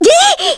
Miruru-Vox_Damage_kr_01.wav